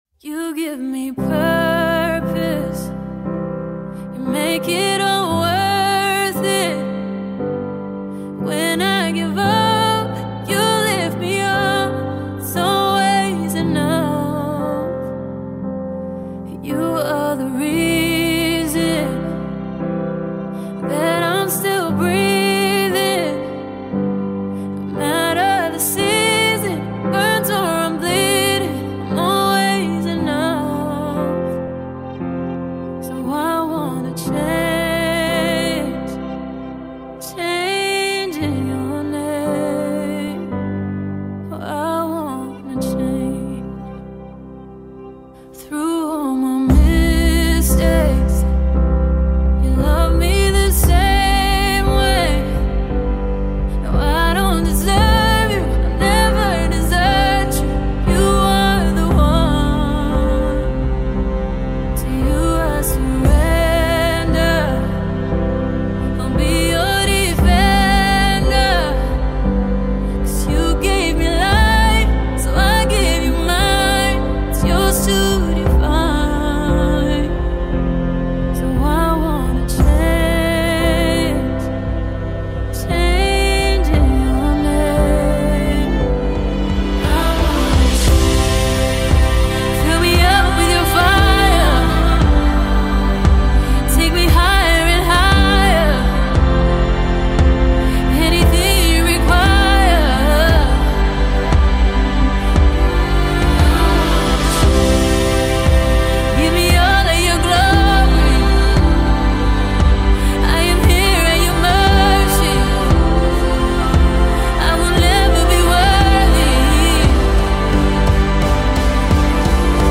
cinematic pop and soulful gospel
Gospel Songs